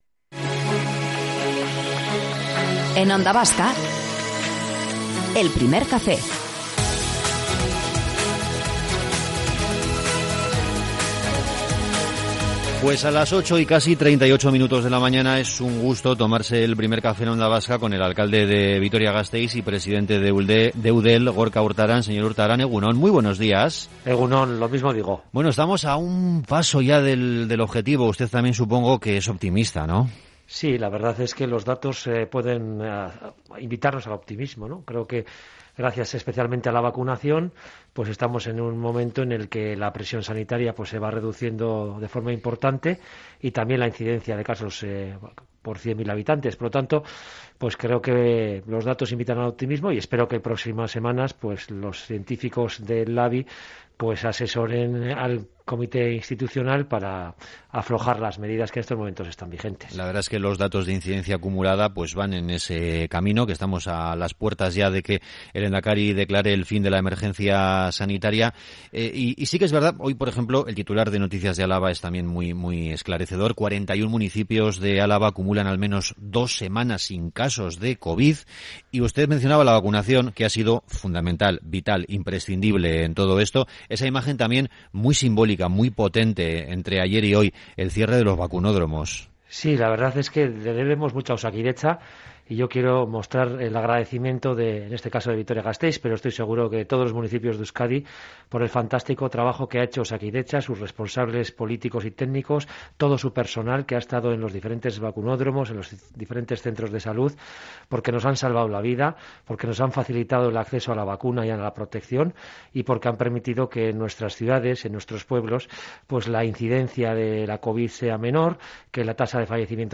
Entrevista completa con Gorka Urtaran - Onda Vasca
Morning show conectado a la calle y omnipresente en la red.